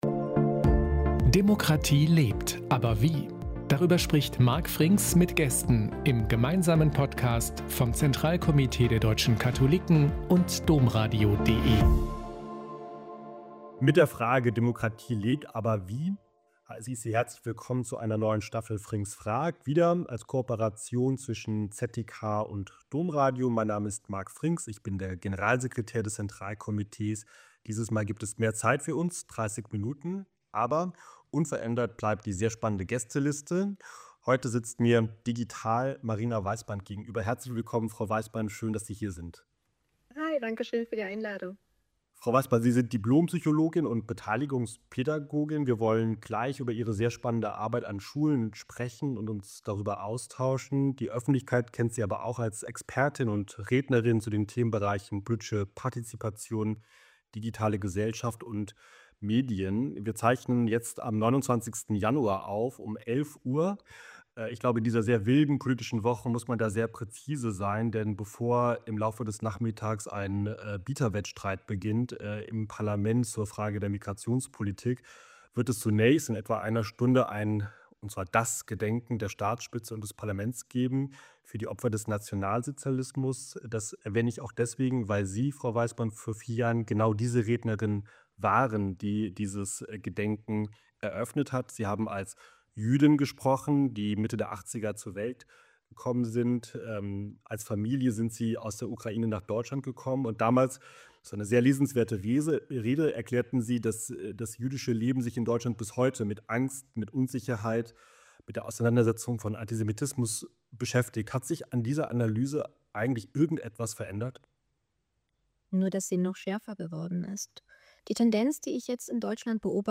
Marina Weisband zu Gast im neuen Podcast